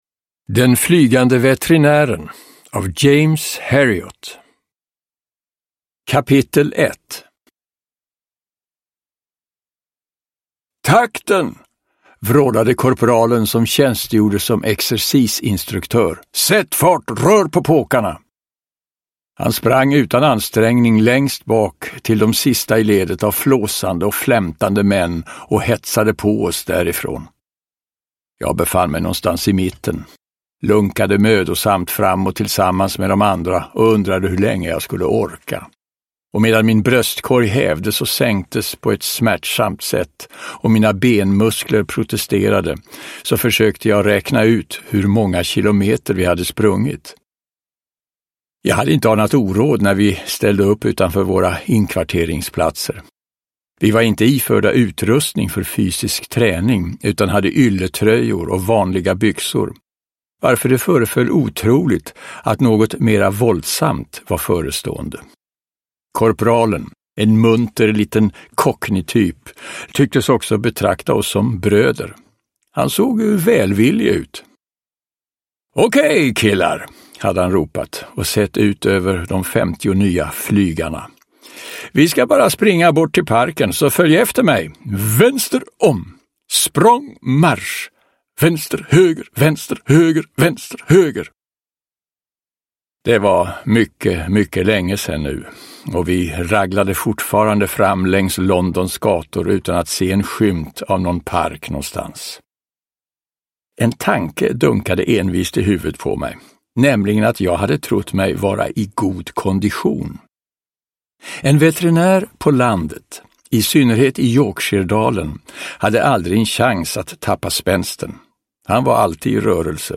Den flygande veterinären (ljudbok) av James Herriot